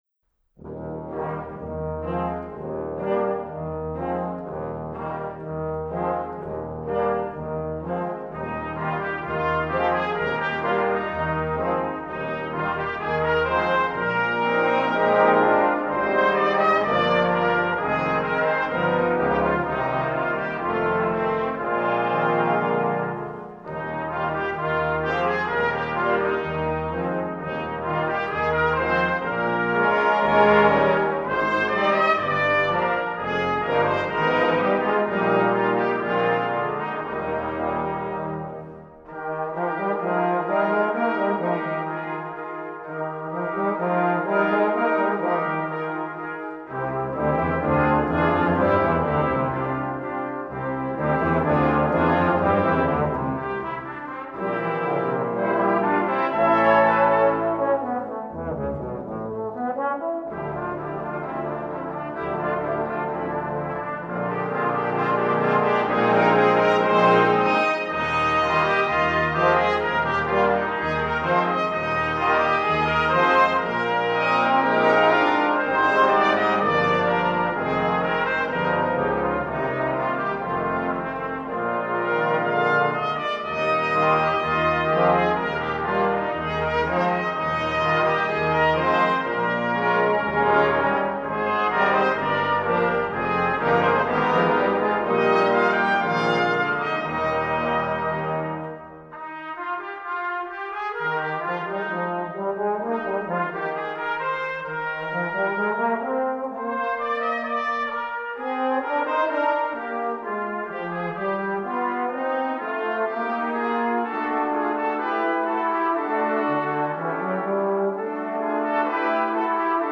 Happy” - DOWNLOAD des 3. Satzes einer Bläserkomposition mit dem Schwäbischen Posaunendienst